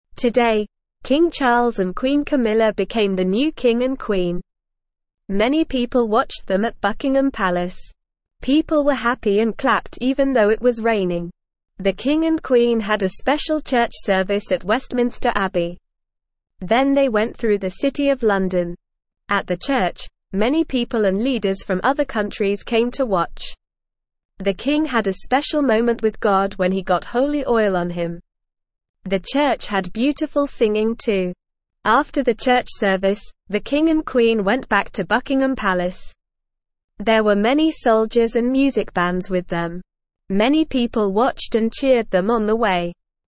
Normal
English-Newsroom-Beginner-NORMAL-Reading-New-King-and-Queen-Celebration-Day.mp3